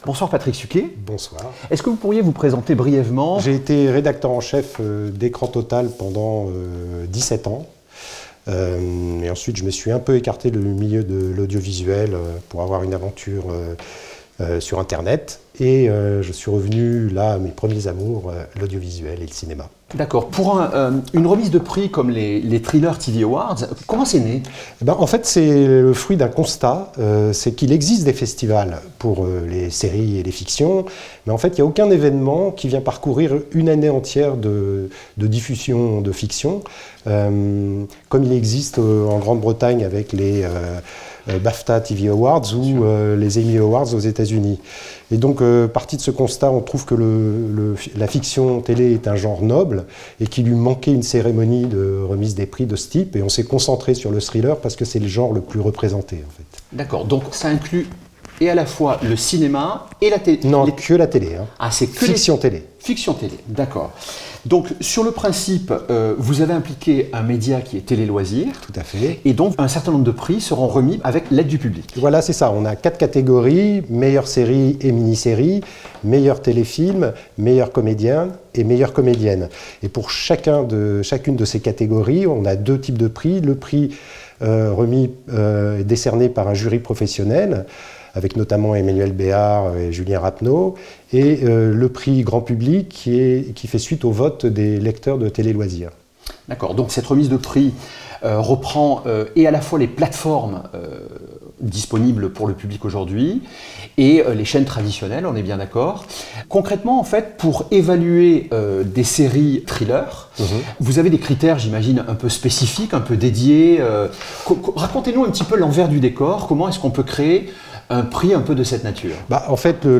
Thriller TV Awards : Rencontre